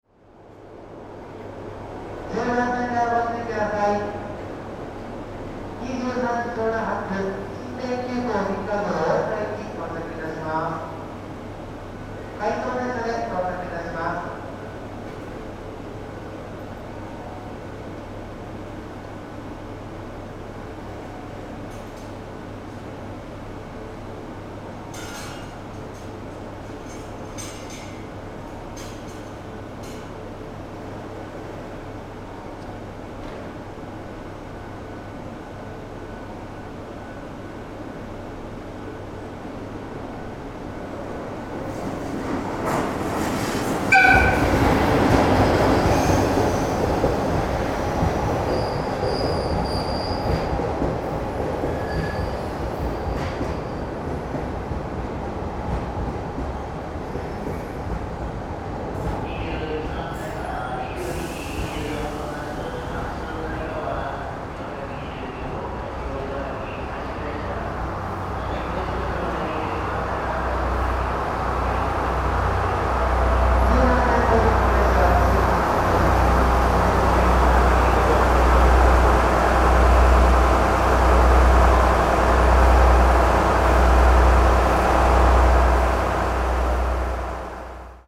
大きな音が出る場合があるのでヘッドフォン推奨です。
東京駅10番線に「銀河」が入線